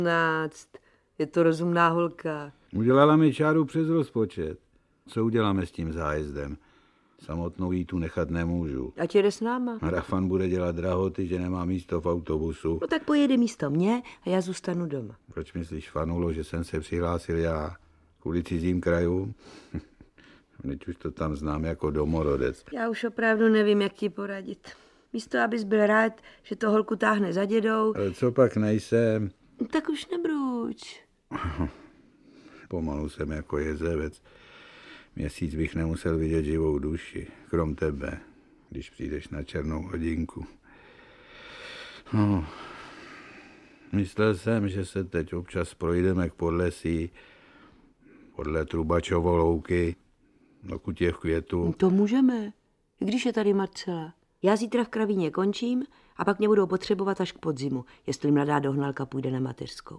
Audiobook
Read: Vlastimil Brodský